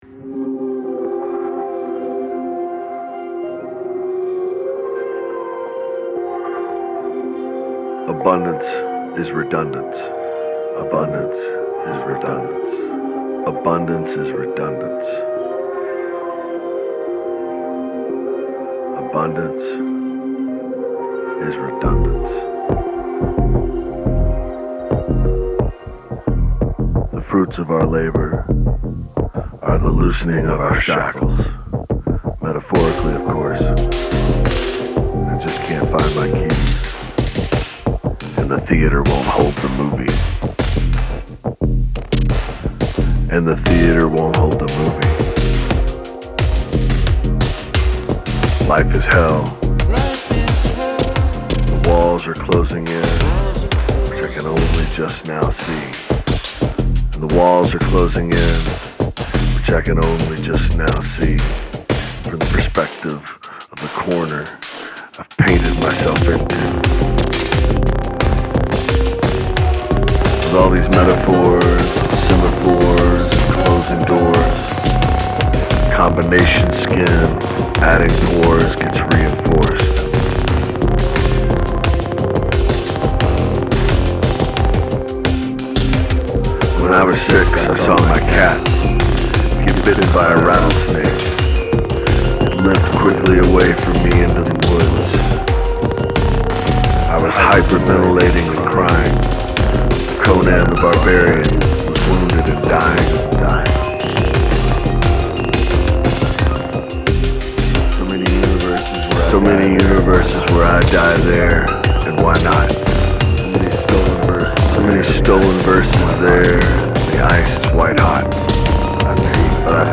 Another buddy came by last night and did a spoken word piece over one of my older Syntakt jams